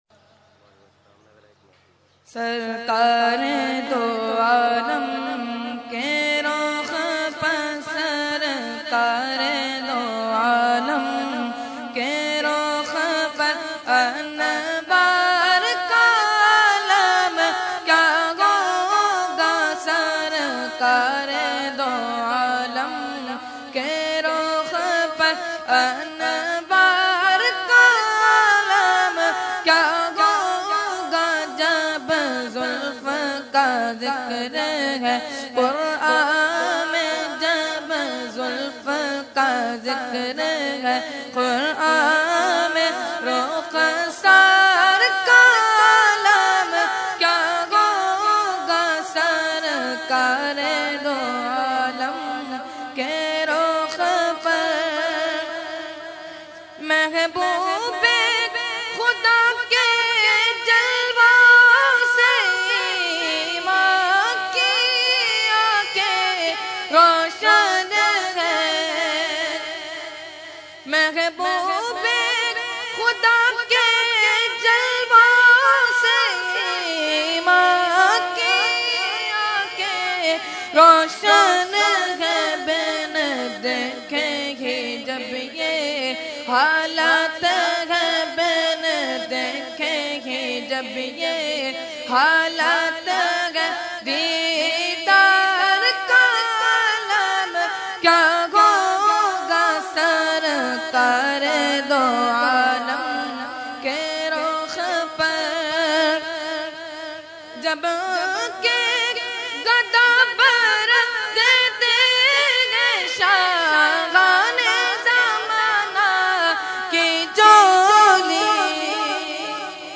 Category : Naat | Language : UrduEvent : Urs e Makhdoom e Samnani 2015